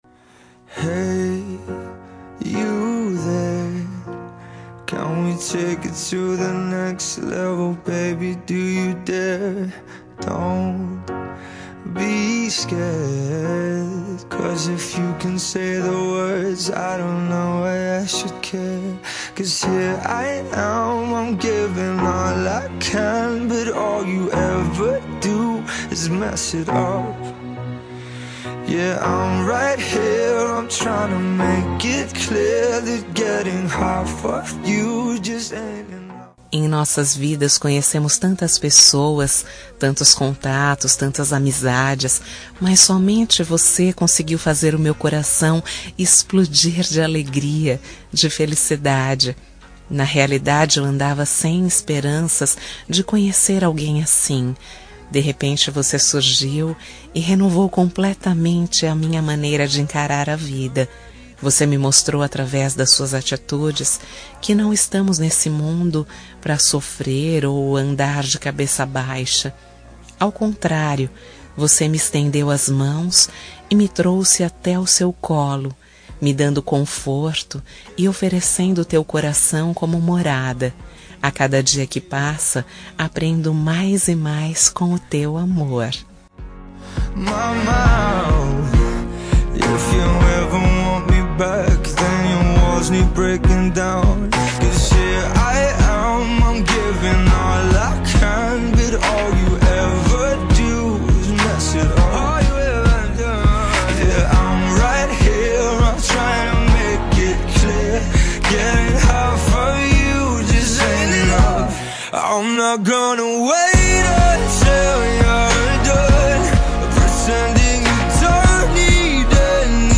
Telemensagem Romântica – Voz Feminina – Cód: 5476 – Linda
5476-gls-rom-fem.m4a